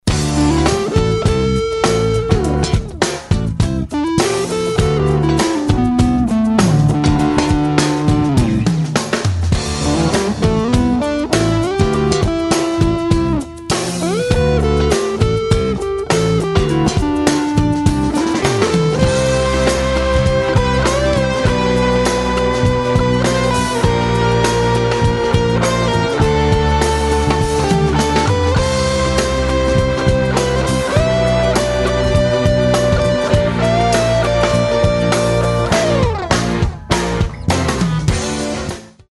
Melodicky dobre, sedi to, dokonca v tom refrene, ci co to je si dal celkom peknu melodiu.
Zvuk - je to bzucak, zneje to ako nejaky efekt vrazeny rovno do PC.
Intonacia - VIBRATO JE GITARISTOV KAMARAT Si píš!!! A trochu lepsie ladit :)